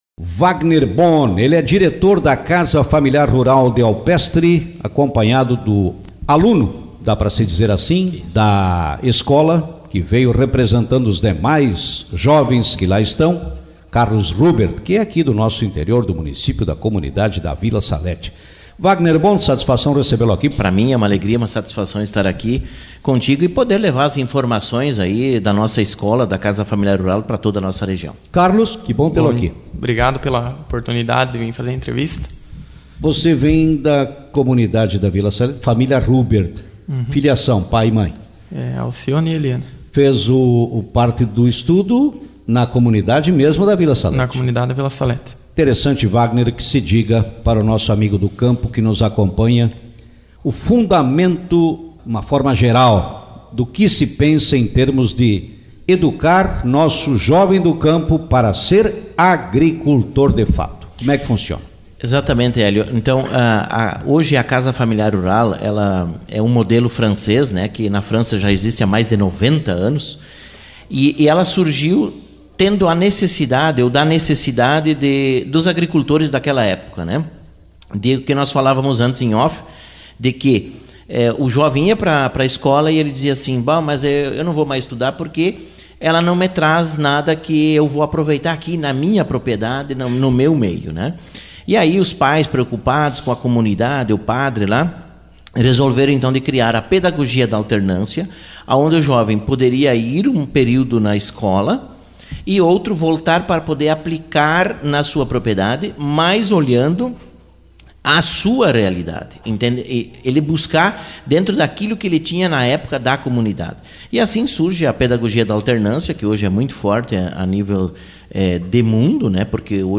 Na manhã desta segunda-feira, 03, integrantes da CASA FAMILIAR RURAL DE ALPESTRE, participaram do programa Café com Notícias, dentre os assuntos abordados, comentaram sobre a condução e forma educacional de suas atividades.